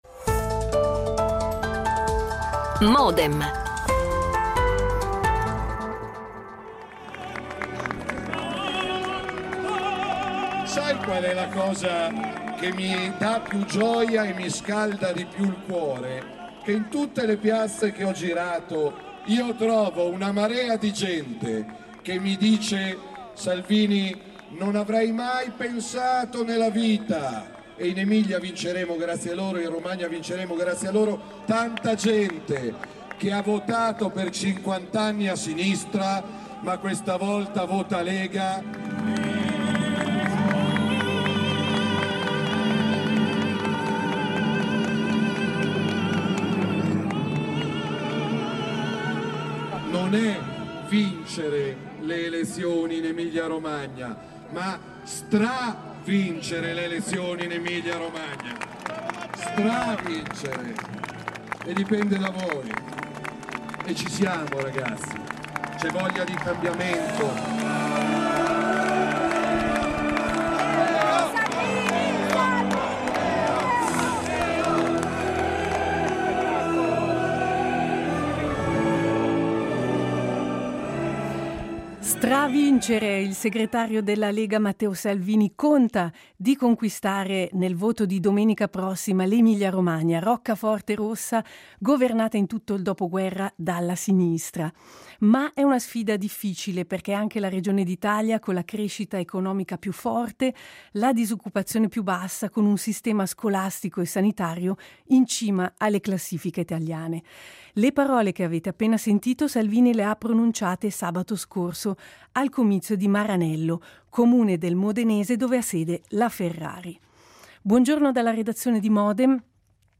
Vi proponiamo un reportage con le voci e le sensazioni da Maranello e da Rimini e poi parliamo dell'accesa campagna politica e della posta in gioco con:
L'attualità approfondita, in diretta, tutte le mattine, da lunedì a venerdì